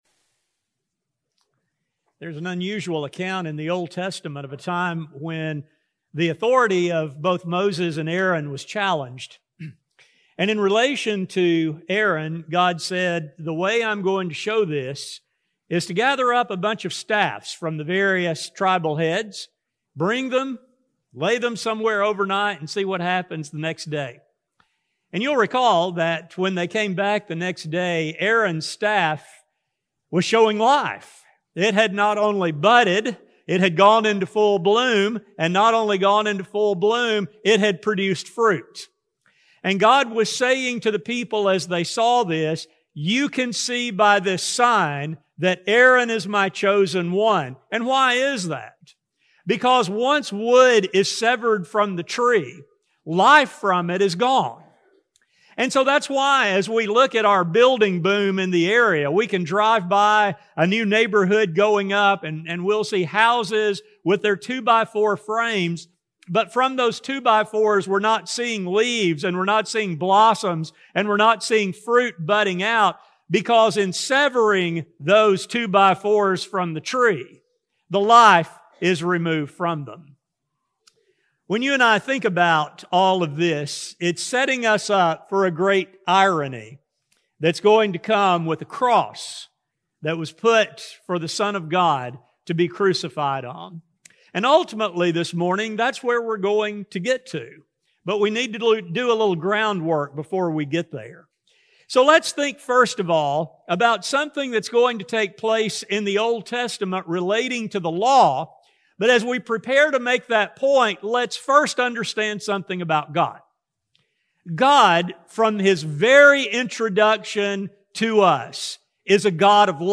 In this study, we'll expolore the connection between the tree, the death of Jesus, and humanity’s hope for eternal life. A sermon recording